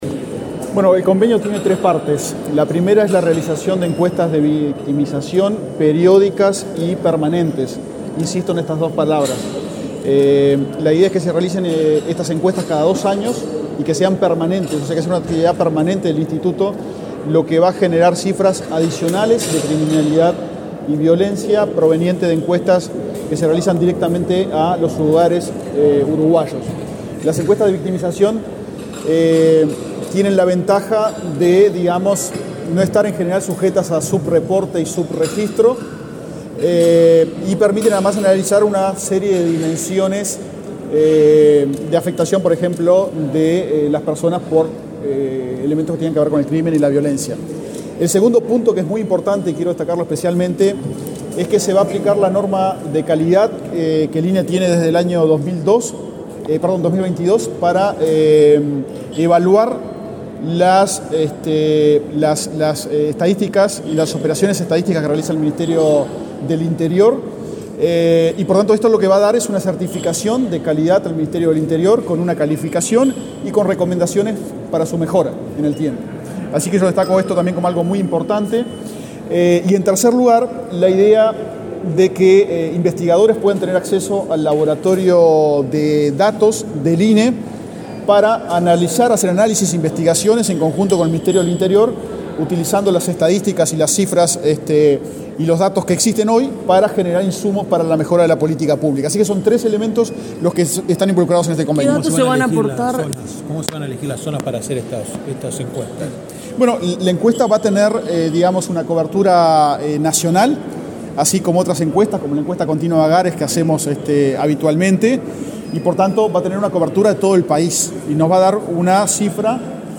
Declaraciones a la prensa del director del INE, Diego Aboal
Este jueves 7 en la Torre Ejecutiva, el director del Instituto Nacional de Estadísticas (INE), Diego Aboal, dialogó con la prensa, antes de participar